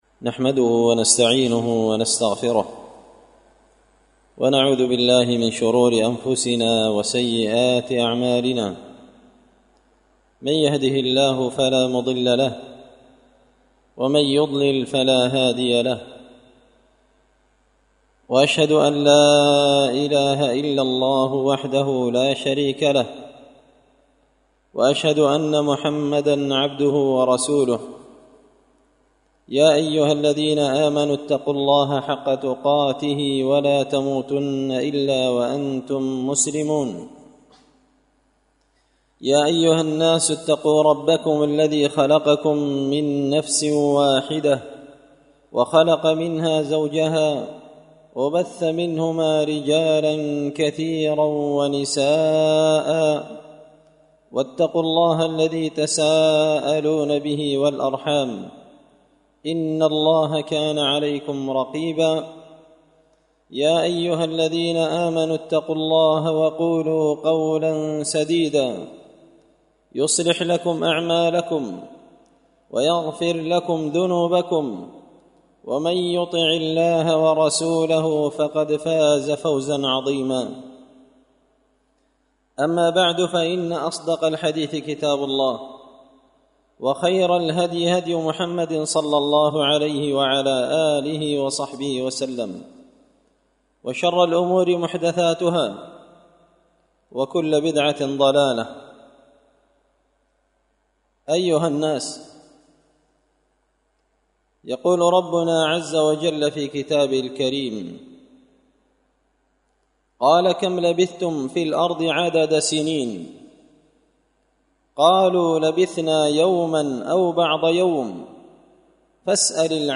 خطبة جمعة بعنوان – اغتنم حياتك قبل موتك
دار الحديث بمسجد الفرقان ـ قشن ـ المهرة ـ اليمن